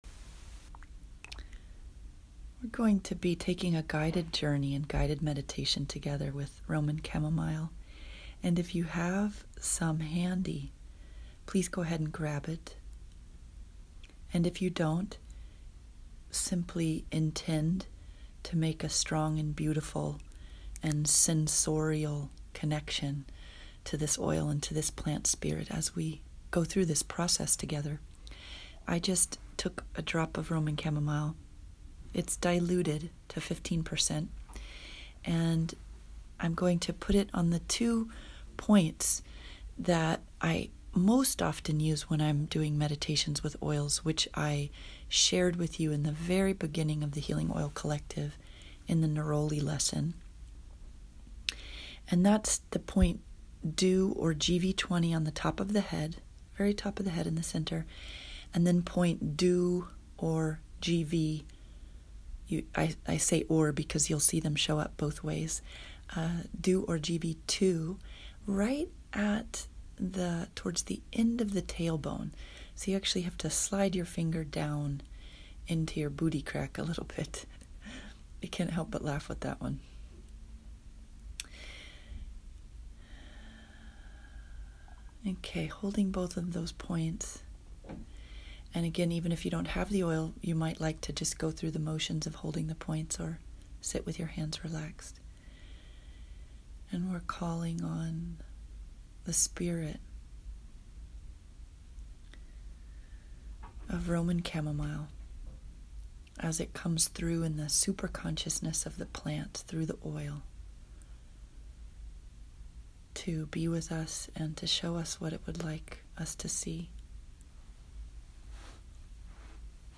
Roman Chamomile Guided Meditation